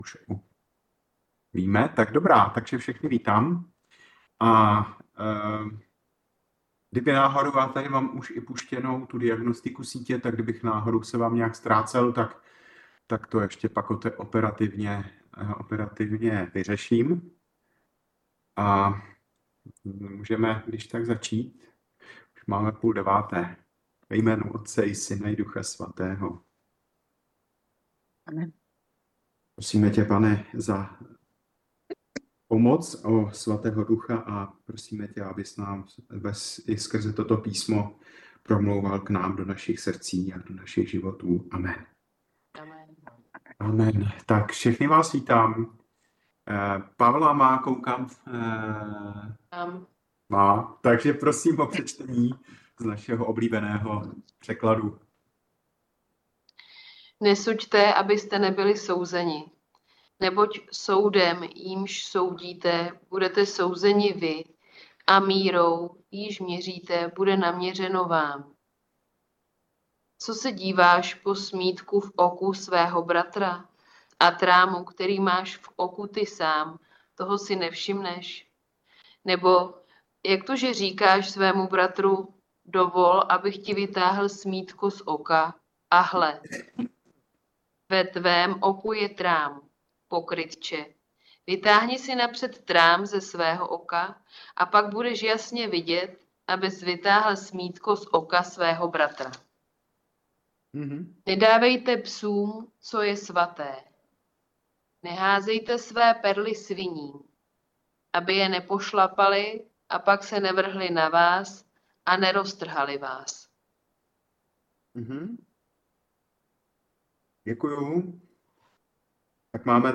Biblické hodiny | Římskokatolická farnost u kostela sv.
Na této stránce najdete zvukové záznamy z našich on-line biblických hodin.